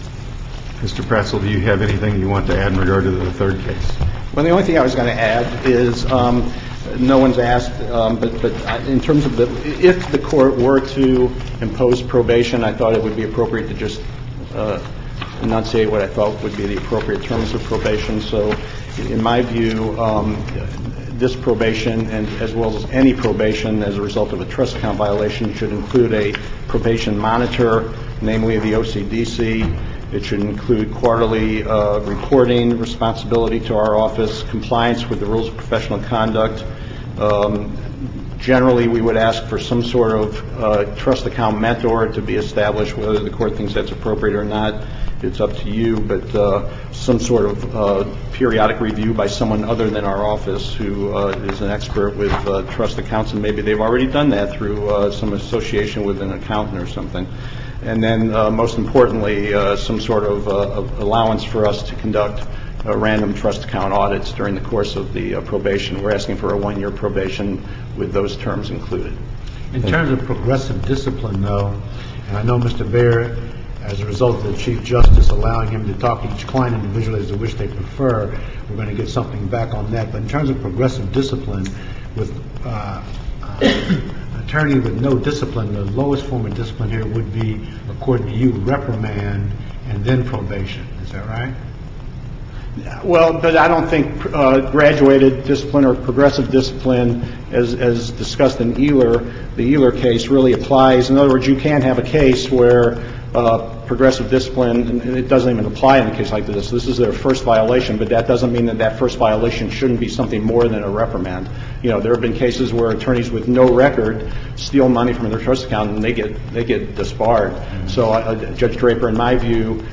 SUPREME COURT OF MISSOURI